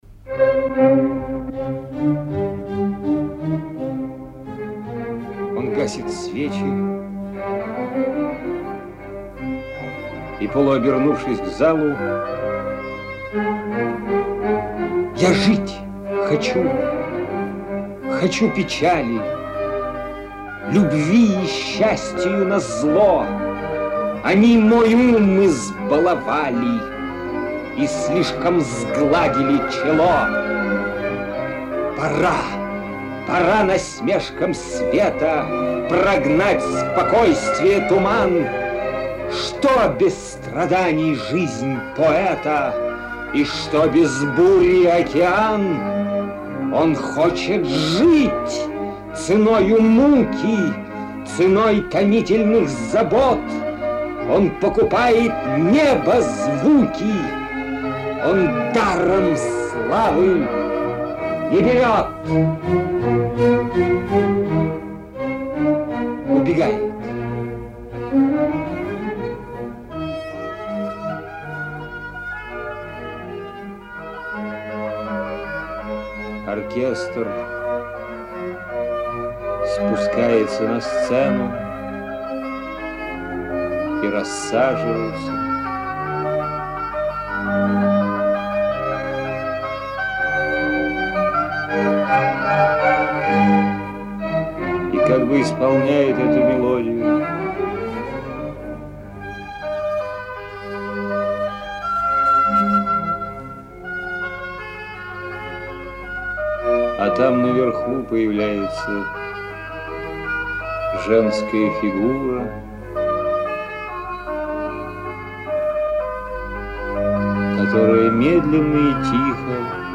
Lermontov.-Ya-zhit-hochu.-CHitaet-Oleg-Dal-stih-club-ru.mp3